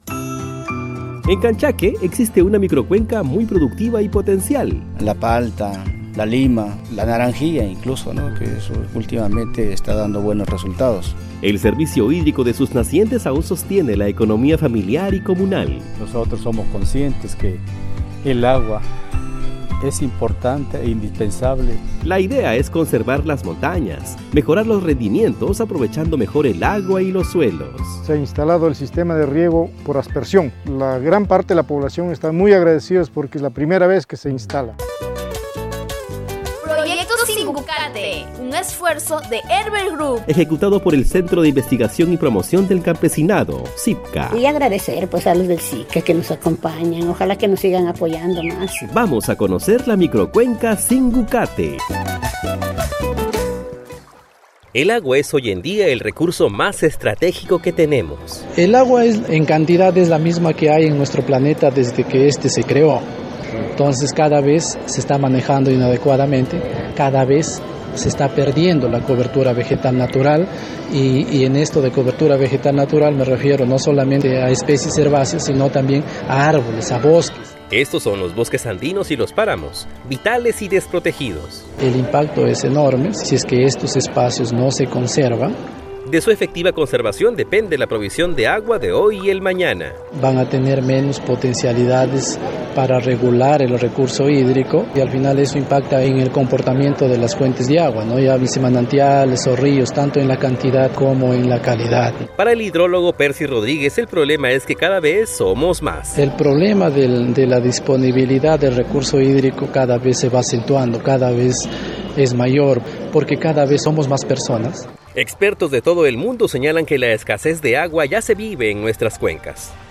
REPORTAJE-SINGUCATE-1era-parte.mp3